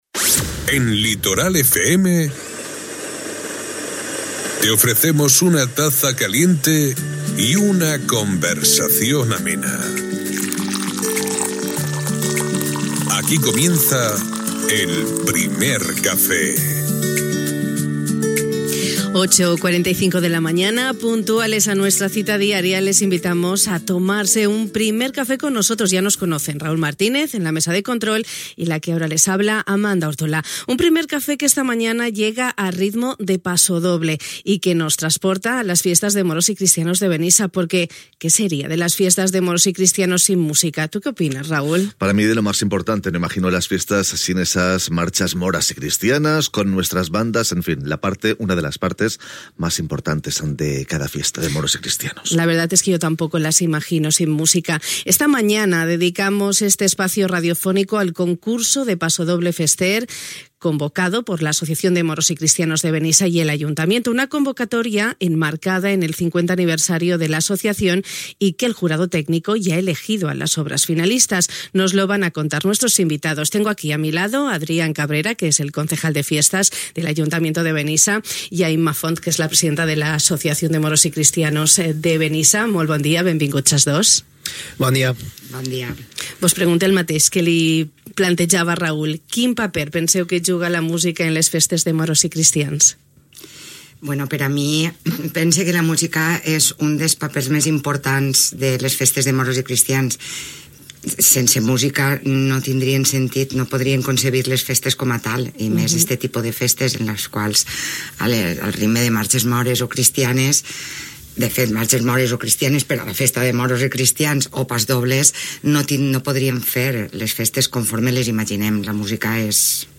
La música és part essencial de la festa, així ho han posat de manifest els nostres convidats al Primer Café de hui a Ràdio Litoral. Aquest matí hem dedicat temps de ràdio al Concurs de Composició de Pasdoble Fester convocat per l’Associació de Moros i Cristians de Benissa i l’Ajuntament de la localitat.